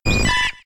Cri de Chenipan K.O. dans Pokémon X et Y.